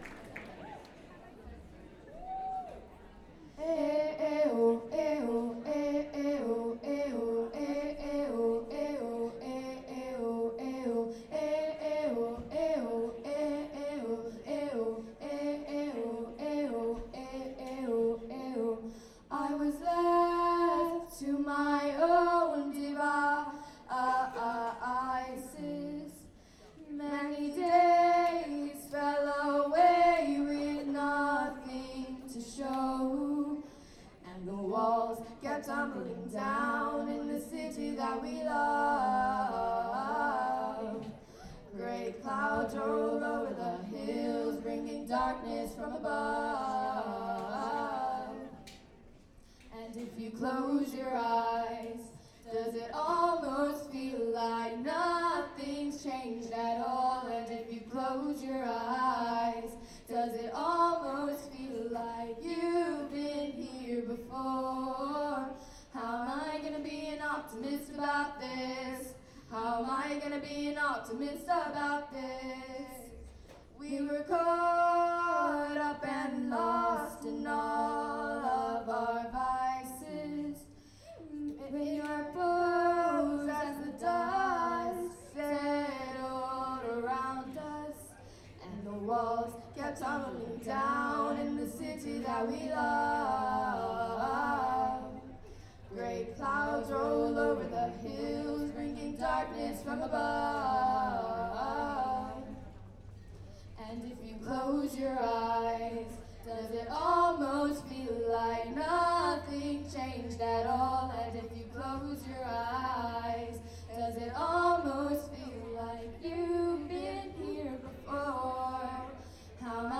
lifeblood: bootlegs: 2016-01-03: terminal west - atlanta, georgia (benefit for save the libraries)